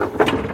PixelPerfectionCE/assets/minecraft/sounds/tile/piston/in.ogg at mc116